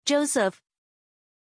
Pronunciation of Joseph
pronunciation-joseph-zh.mp3